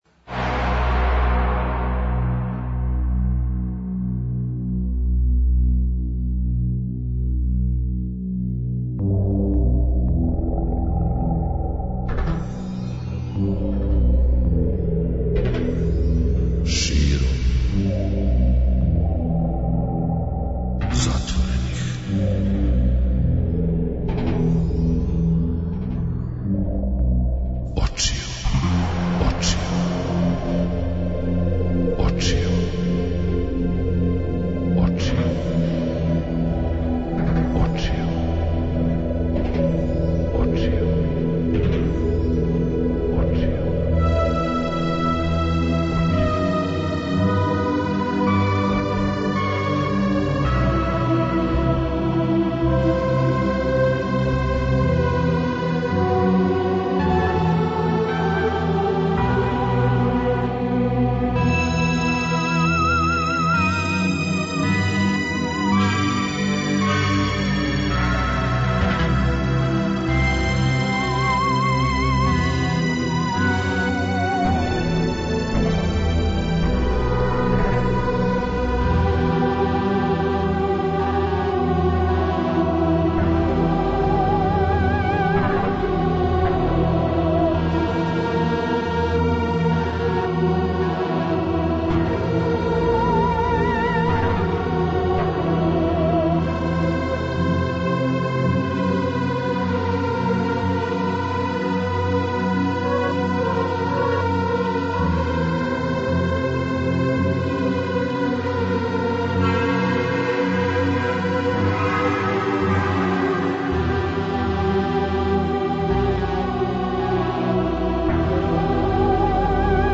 преузми : 50.23 MB Широм затворених очију Autor: Београд 202 Ноћни програм Београда 202 [ детаљније ] Све епизоде серијала Београд 202 Устанак Устанак Устанак Блузологија Свака песма носи своју причу